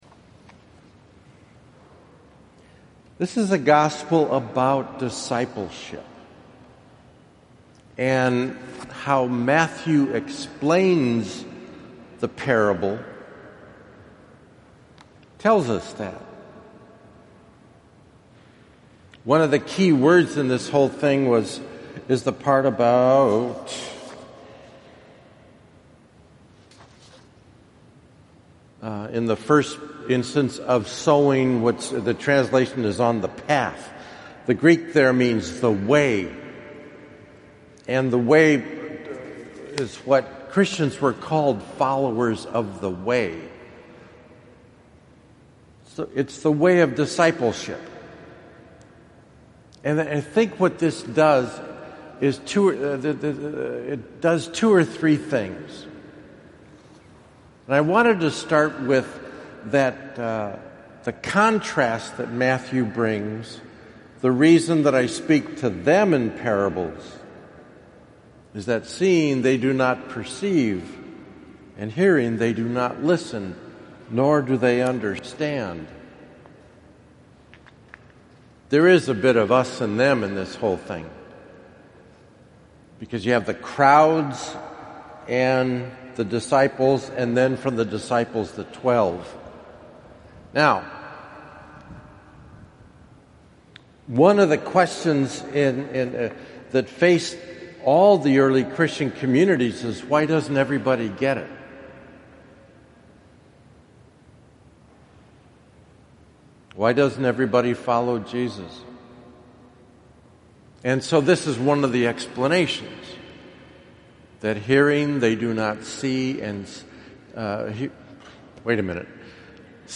I thought I’d try this: to publish my three homilies this week on the parables of the Reign of God from chapter thirteen in the gospel of Matthew.